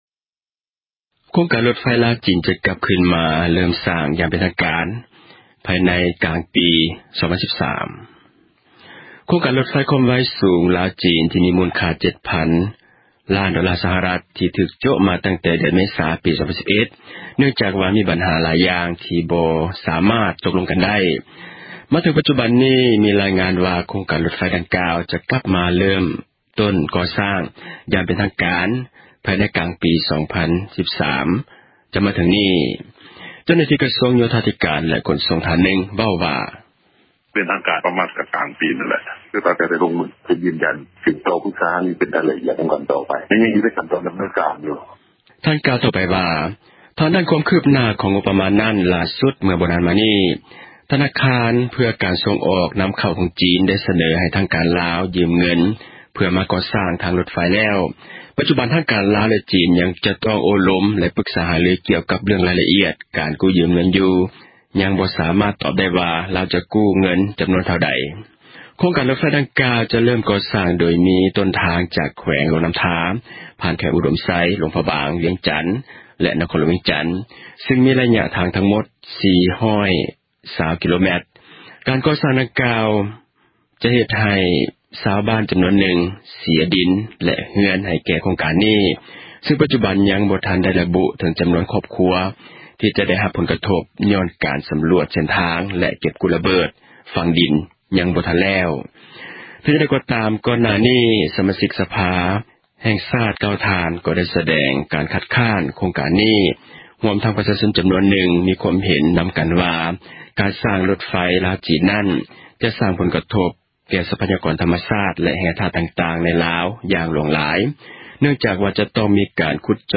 ເຈົ້າໜ້າທີ່ ກະຊວງໂຍທາທິການ ແລະ ຂົນສົ່ງ ທ່ານນຶ່ງ ເວົ້າວ່າ: